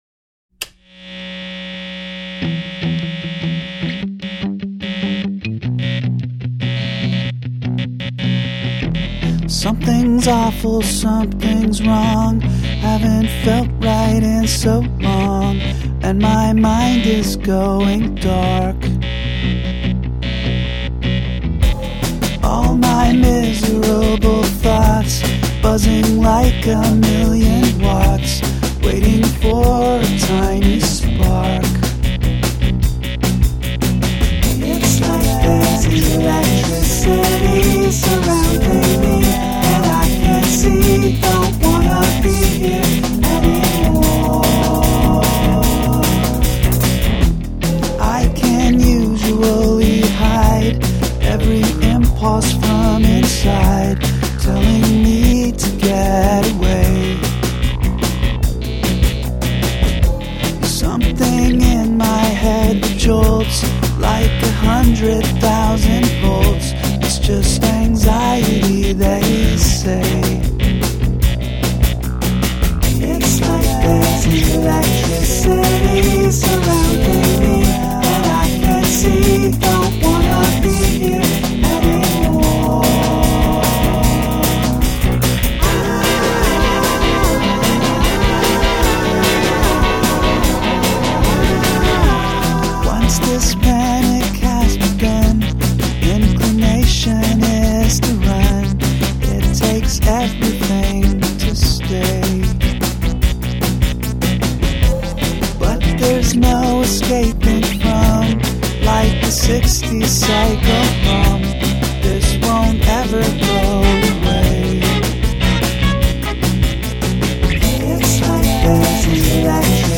turned cable static into a melodic and rhythmic element